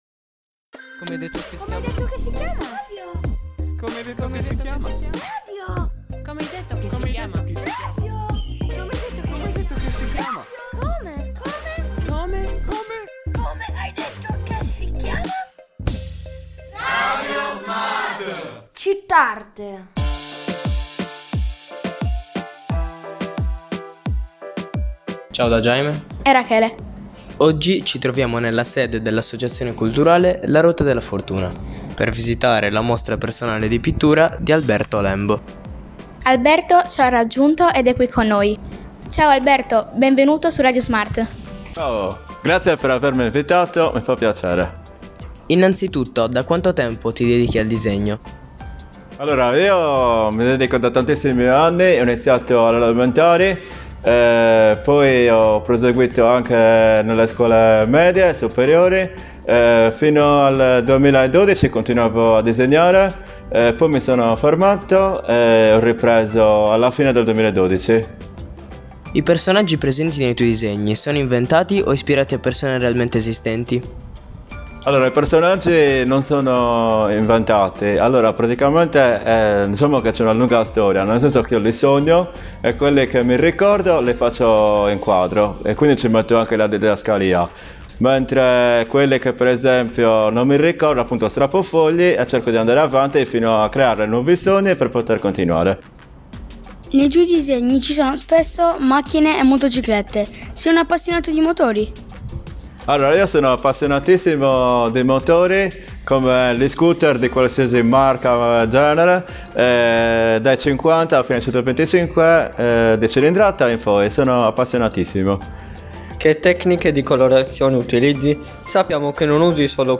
In questa intervista abbiamo avuto il piacere di conoscere meglio l’artista, di farci raccontare le varie tecniche per la realizzazione dei suoi dipinti che nascono dai suoi sogni oltre che dalle ispirazioni che trova nella natura intorno a sé.